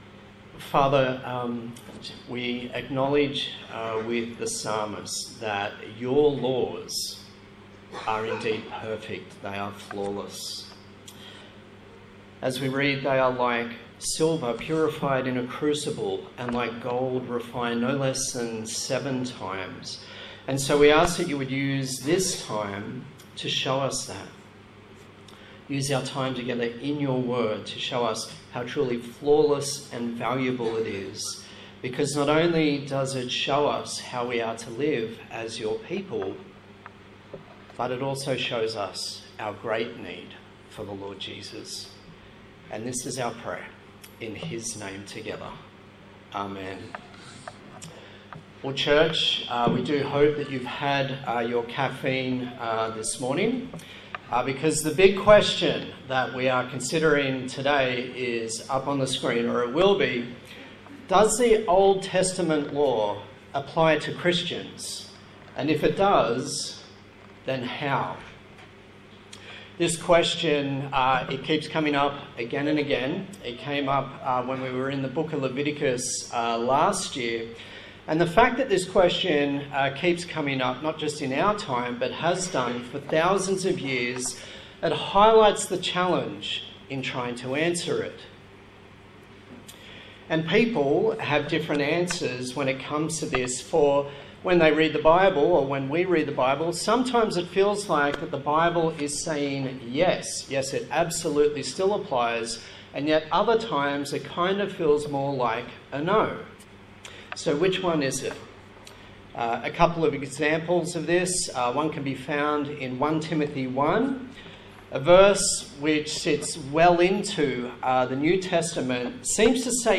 A sermon
Kingdom Come Passage: Matthew 5:17-20 Service Type: Sunday Morning